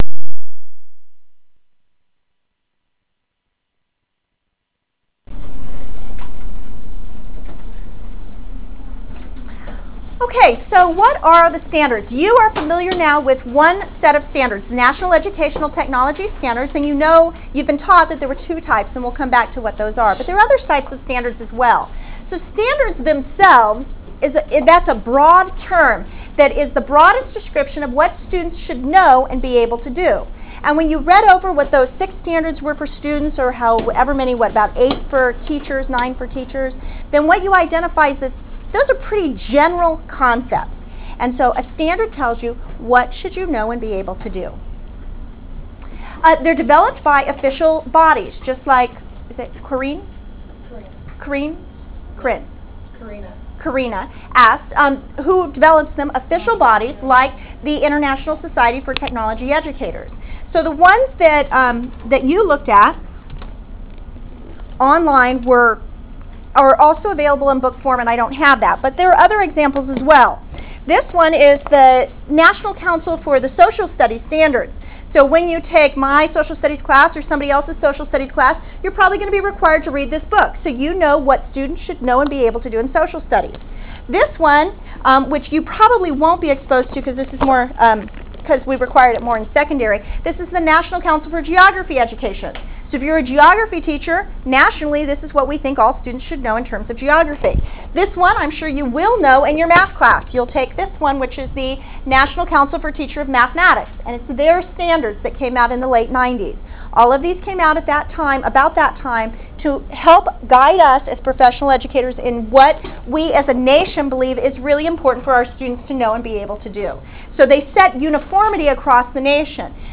Lecture: Standards, Curriculum, and Instruction (30 minutes) - see lecture slides ; hear audio Define “standards.”
Lecture_ Standards_Curriculum_Instruction_NETS_9_6_06.wav